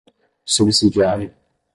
Pronunciado como (IPA) /su.bi.zi.d͡ʒiˈa.ɾi.u/